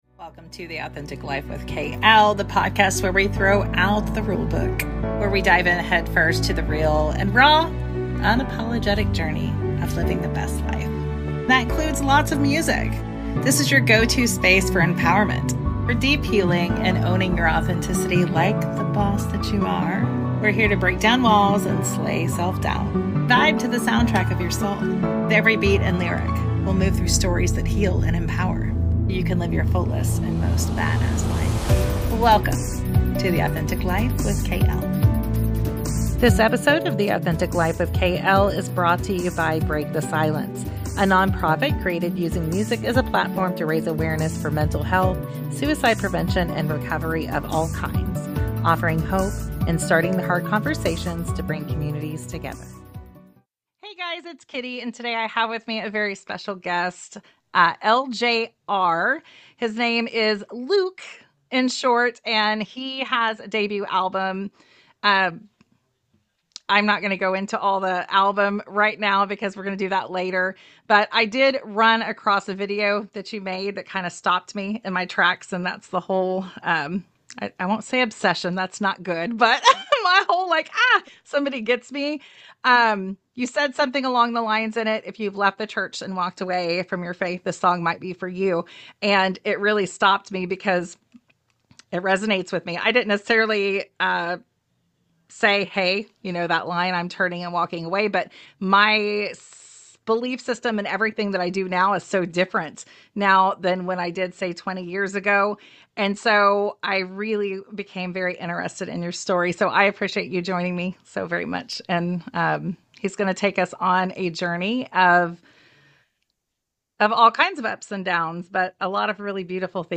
Today’s conversation is especially meaningful.